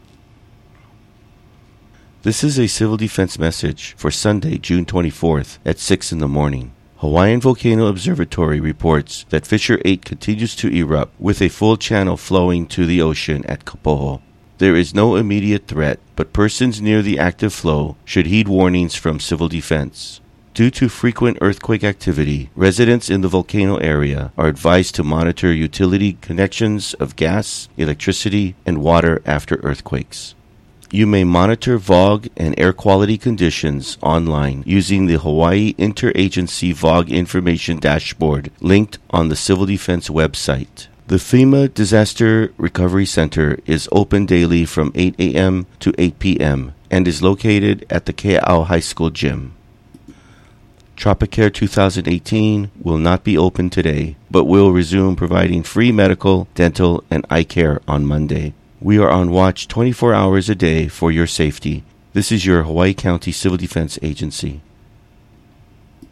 This is a Civil Defense Message for 6 a.m., Sunday, June 24, 2018.